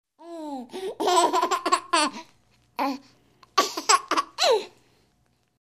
На этой странице собраны звуки детского смеха — звонкие, задорные и трогательные.
Звук детского смеха, будто из фильма ужасов с эхом (воспоминание прошлого)